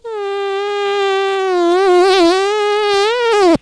Sound of a Girl Farting
fart-sound-4.wav